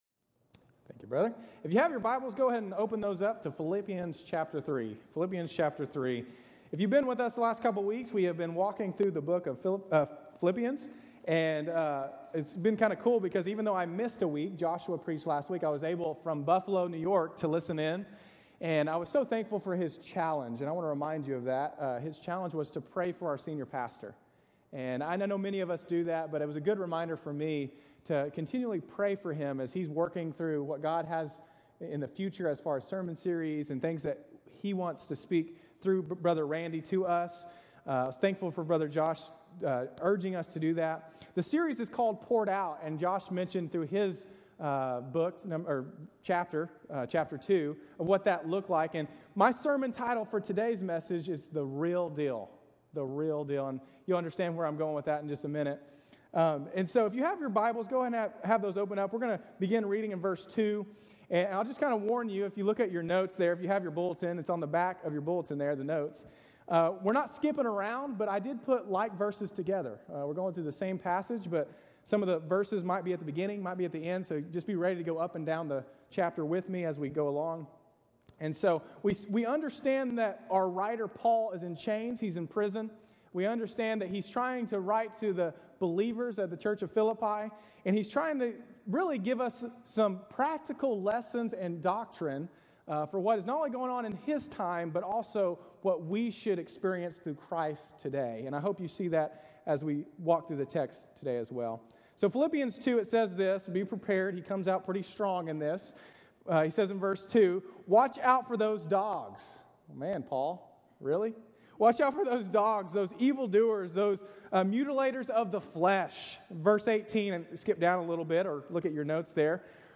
Our pastors will tag team during this 4-week series, to share how we can learn from this ancient text and become more like Jesus by becoming “poured out.”